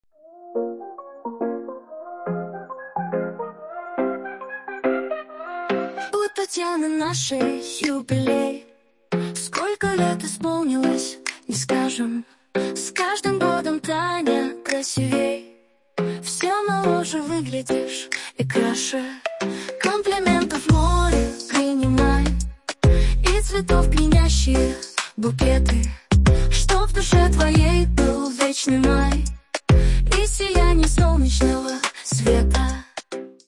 Фрагмент 1 варианта исполнения (женским голосом):